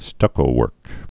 (stŭkō-wûrk)